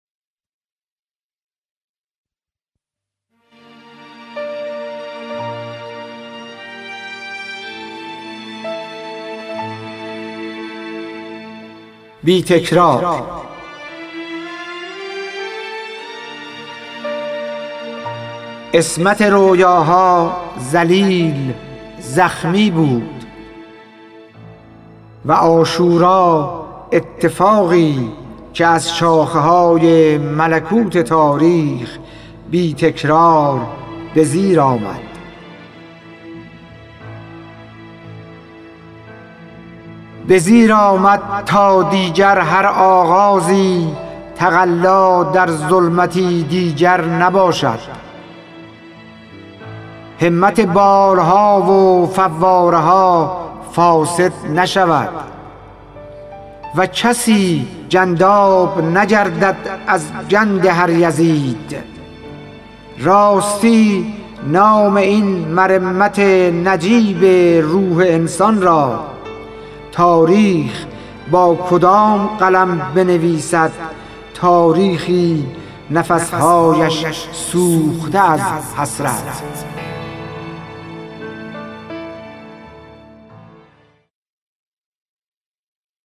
خوانش شعر سپید عاشورایی / ۳